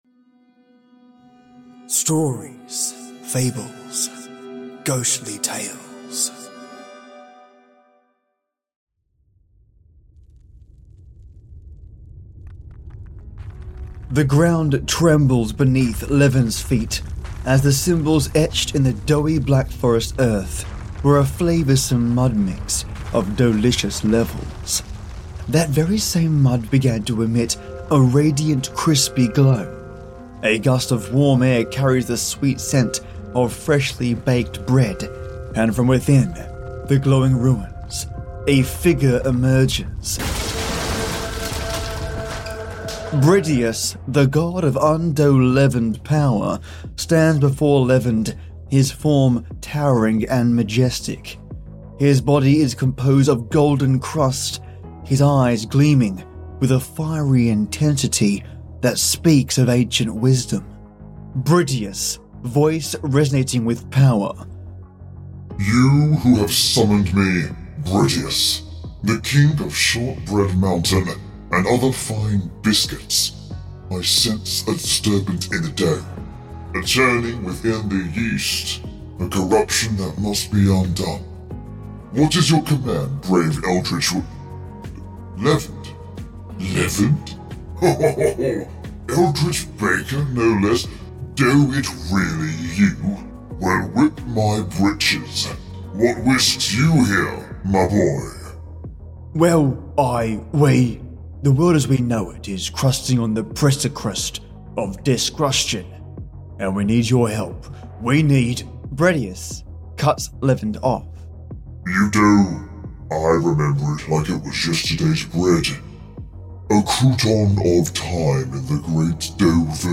You're going to want to keep your headphones on for this one mates because it's crammed full of awesome music, and delicious bread puns....